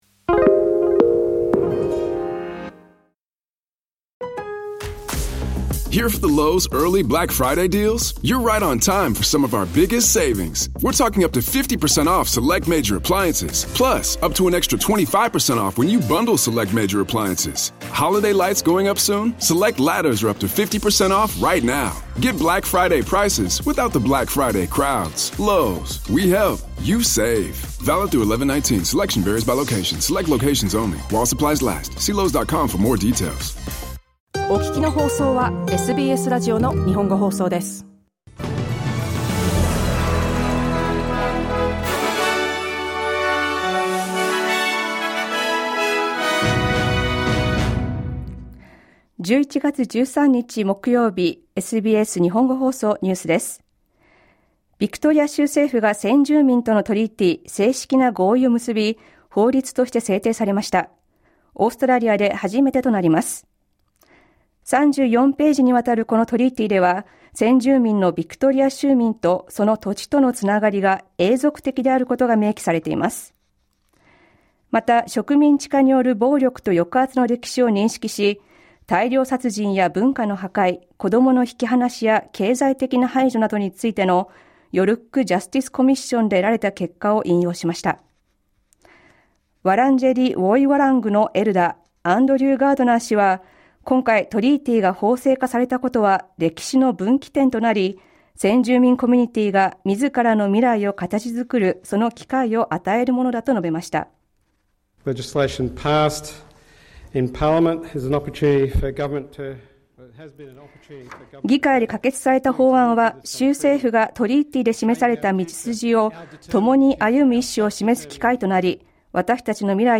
News from today's live program (1-2pm).